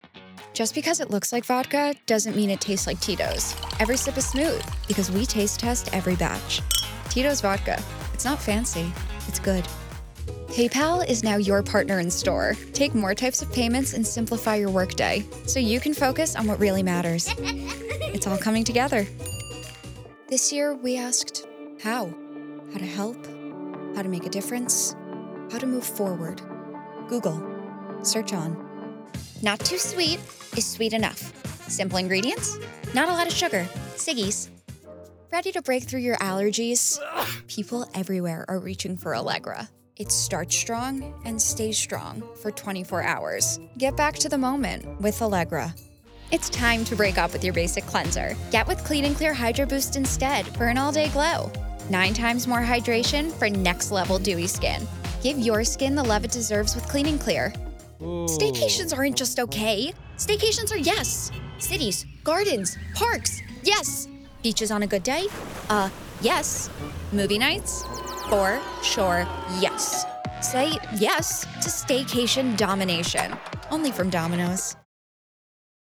Voiceover : Commercial : Women
Commercial Demo